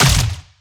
Plasma Rifle
LASRGun_Plasma Rifle Fire_05_SFRMS_SCIWPNS.wav